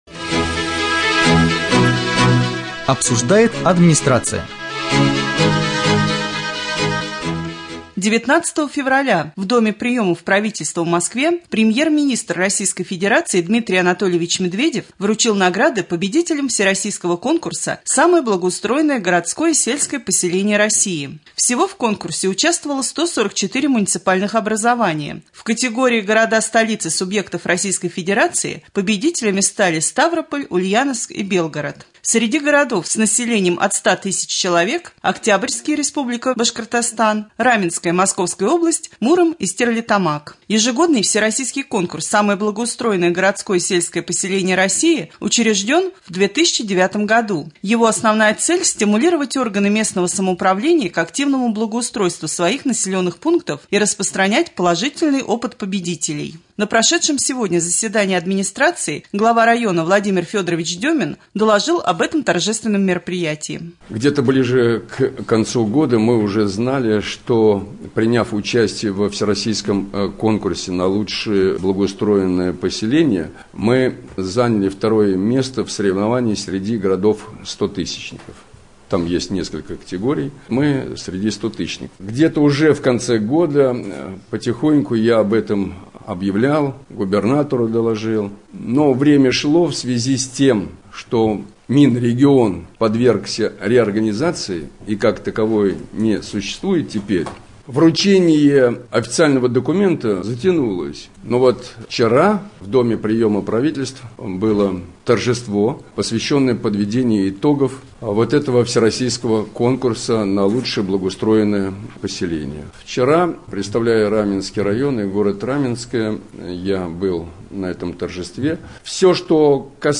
20.02.2015г. в эфире Раменского радио - РамМедиа - Раменский муниципальный округ - Раменское